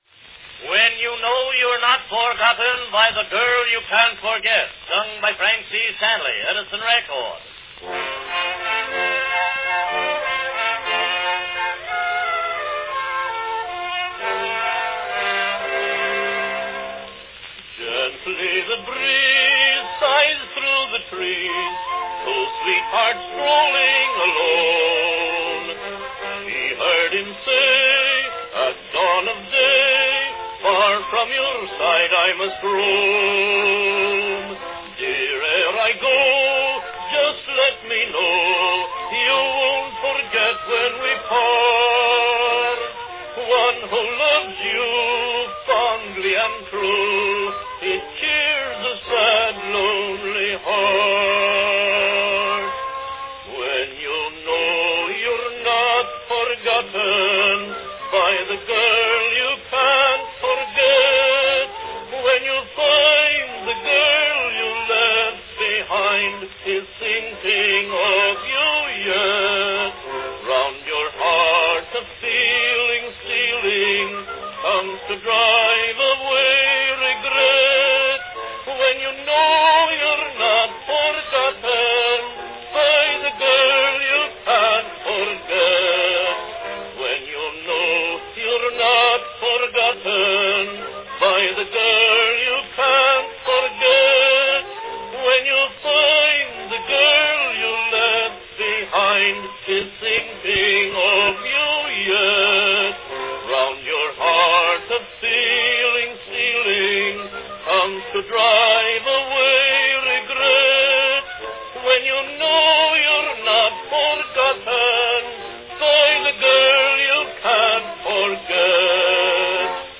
A sentimental song of romance
Category Baritone
a waltz song
The Record is made with orchestra accompaniment.